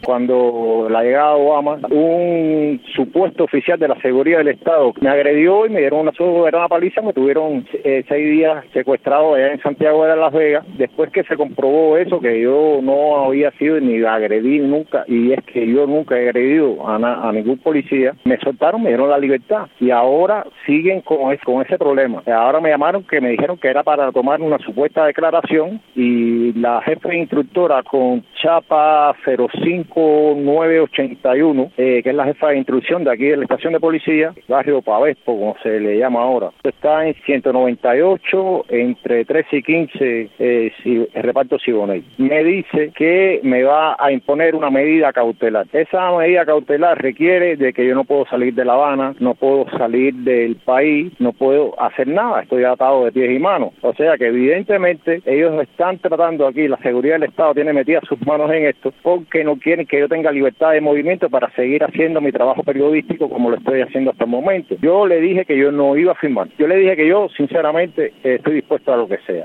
El reportero contó a Martí Noticias los detalles de su encuentro con las autoridades desde la estación policial a la que fue citado.